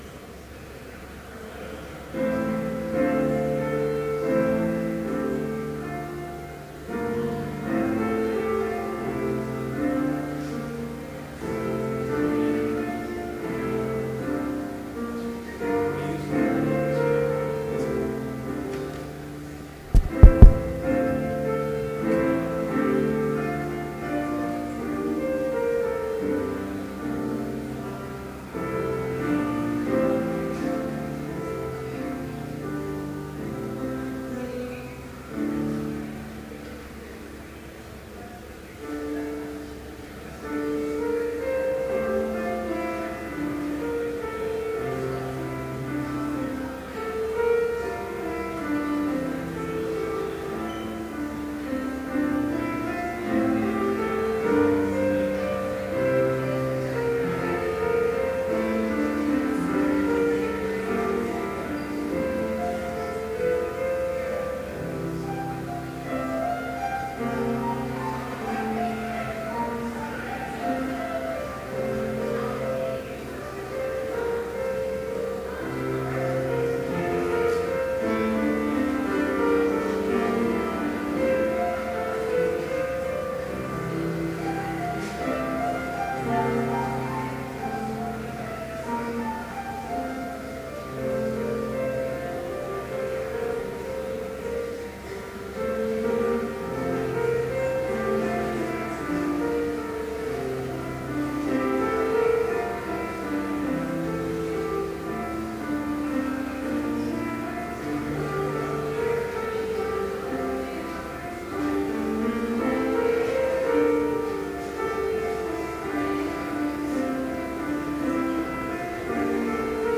Chapel worship service held on September 28, 2012, BLC Trinity Chapel, Mankato, Minnesota, (audio available)
Complete service audio for Chapel - September 28, 2012
Listen Complete Service Audio file: Complete Service Order of Service Prelude Introductory comments on Melanchthon's hymn v. 1 - sung by soloist in Latin Followed by vv. 1-4 in English sung by the assembly.